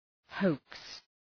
{həʋks}